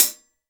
Index of /90_sSampleCDs/AKAI S6000 CD-ROM - Volume 3/Drum_Kit/DRY_KIT2